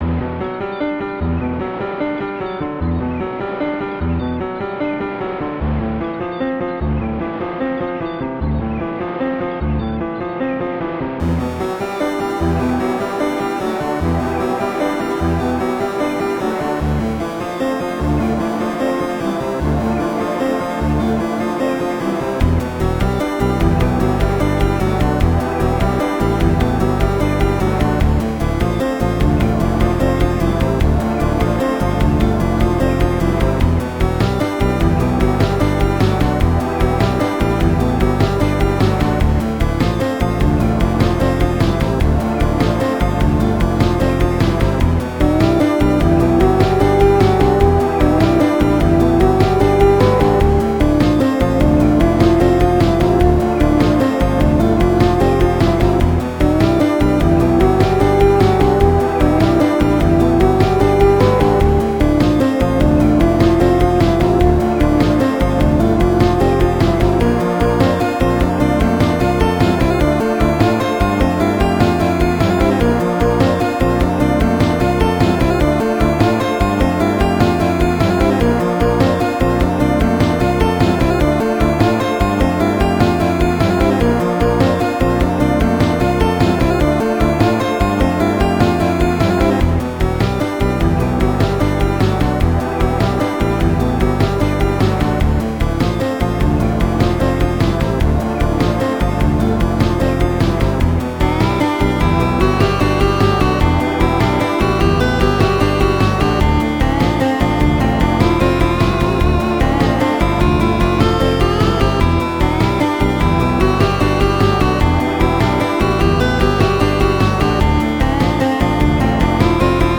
Microplastics [SPC700 + 2A03]
I had this since last year, It's my first 7/4 track made in furnace so i can fuse both chips